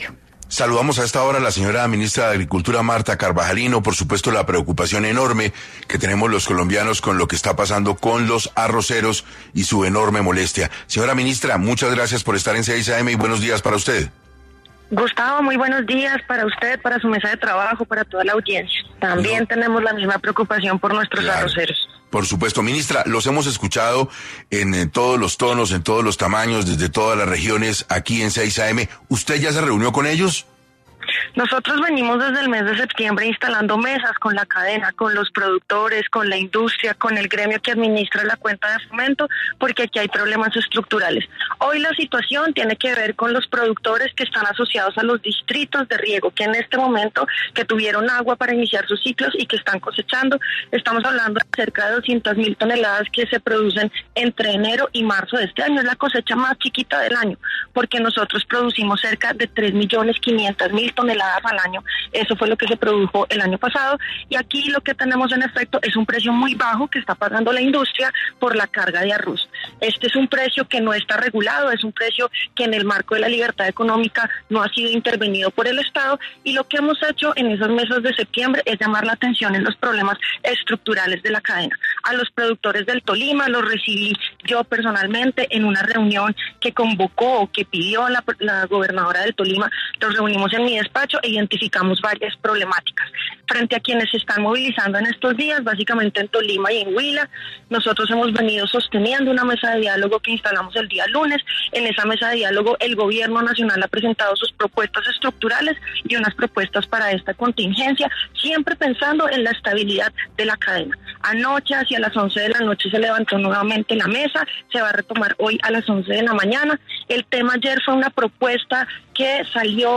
En 6AM de Caracol Radio la ministra de agricultura, Martha Carvajalino, habló sobre el paro arrocero que se vive en en el país
En entrevista con Caracol Radio, la ministra de agricultura, Martha Carvajalino, aseguró que el gobierno ha puesto sobre la mesa diferentes propuestas para solucionar los problemas por los cuales se está desarrollando el paro arrocero en varias regiones del país.